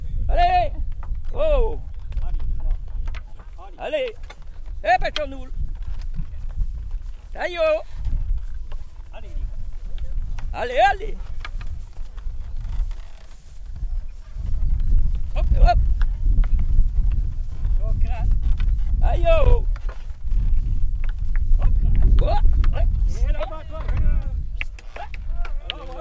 Cris pour mener les boeufs
couplets vocalisés
chant pour mener les boeufs constitué d'onomatopées, interjections et de noms de boeufs
Enquête EthnoDoc et Arexcpo dans le cadre des activités courantes des membres des associations